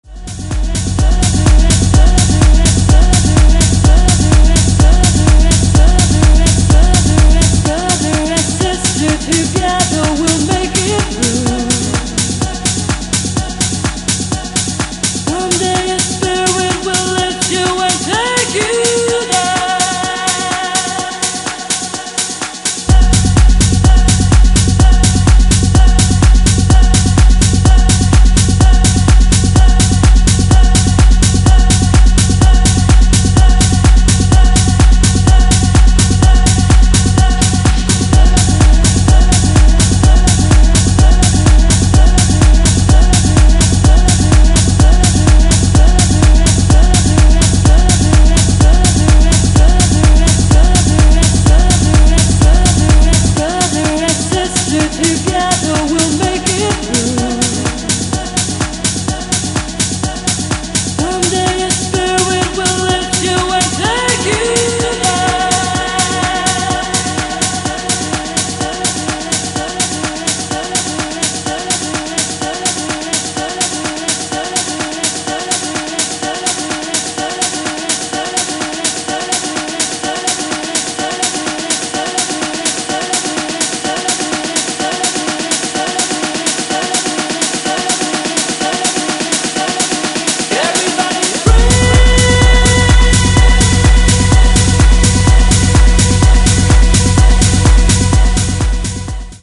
functional, hook-driven techno